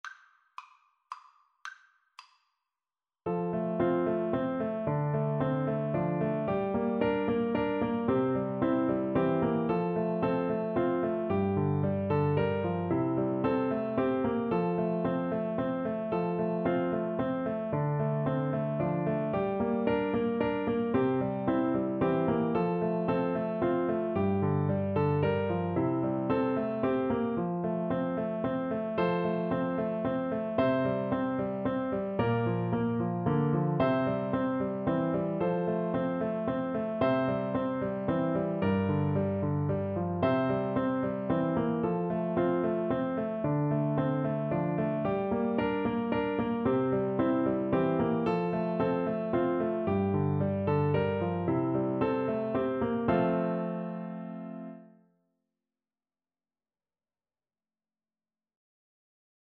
F major (Sounding Pitch) (View more F major Music for Bassoon )
3/4 (View more 3/4 Music)
Moderately Fast ( = c. 112)
Traditional (View more Traditional Bassoon Music)